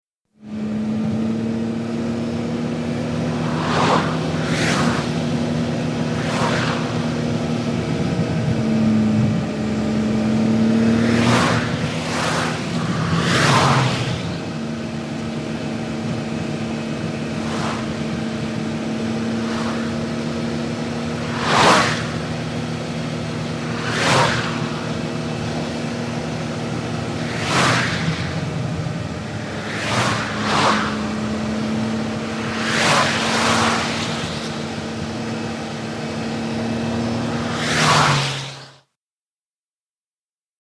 VEHICLES ASTON MARTIN: INT: Constant run on highway with passing cars.